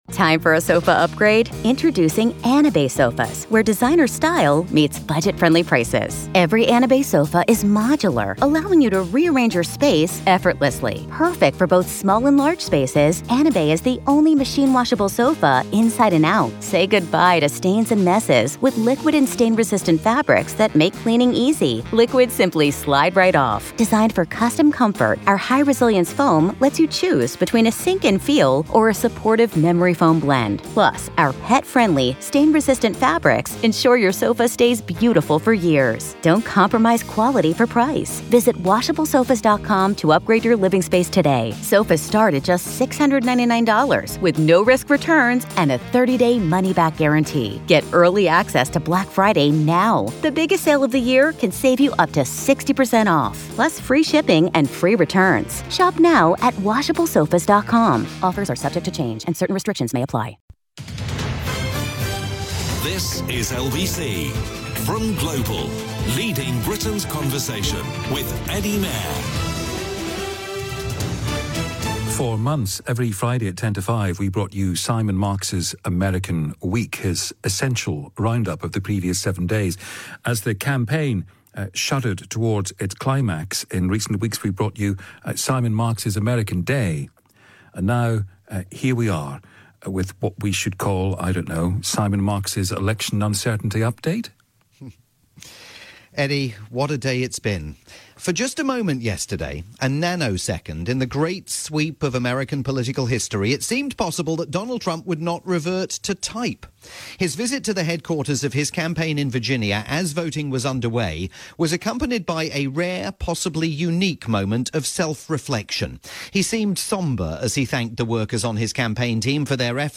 live "American Day" report for Eddie Mair's nightly programme on the UK's LBC.